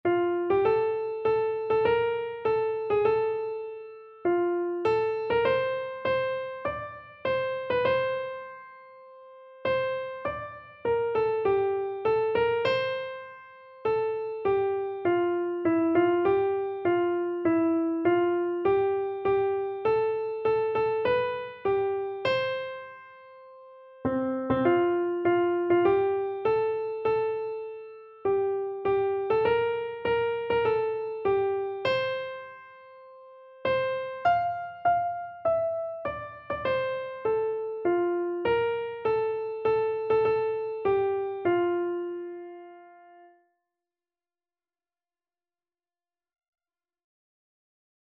Keyboard version
Christian
Free Sheet music for Keyboard (Melody and Chords)
4/4 (View more 4/4 Music)
Classical (View more Classical Keyboard Music)